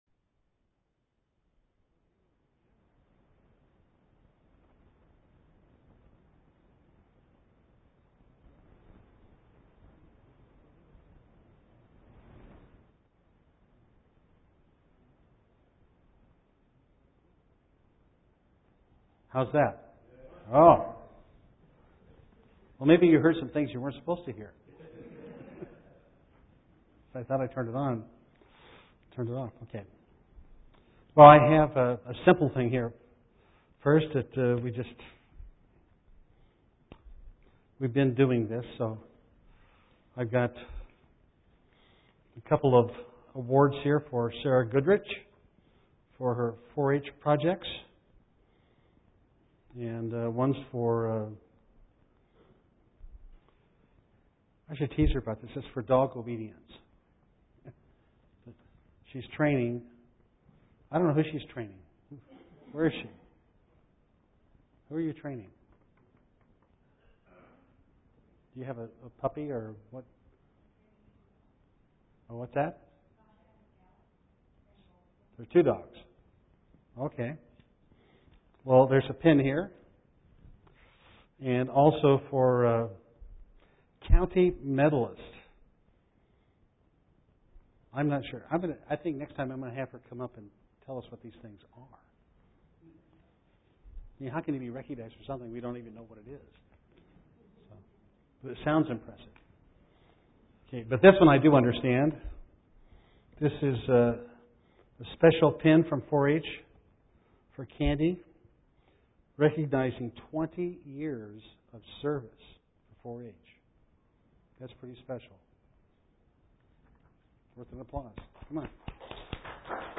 Given in Eureka, CA
UCG Sermon Studying the bible?